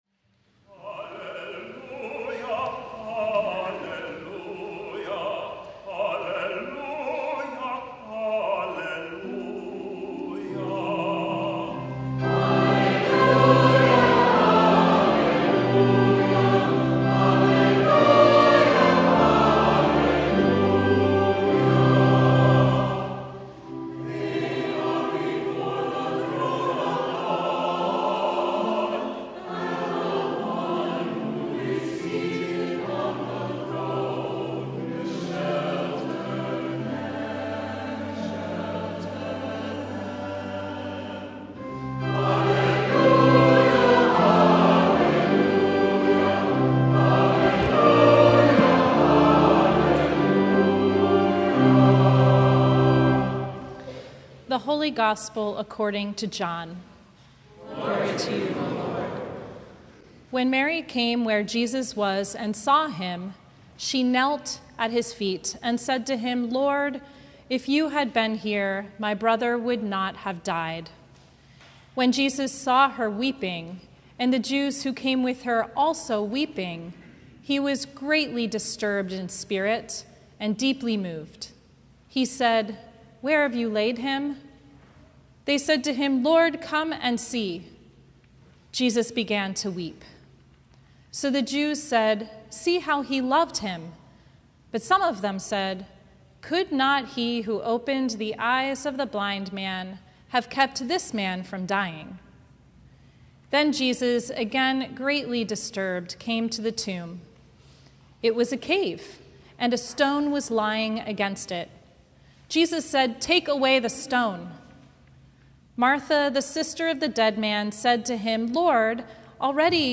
Sermon from All Saints Sunday